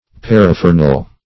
Search Result for " paraphernal" : The Collaborative International Dictionary of English v.0.48: Paraphernal \Par`a*pher"nal\, a. [Cf. F. paraphernal.] Of or pertaining to paraphernalia; as, paraphernal property.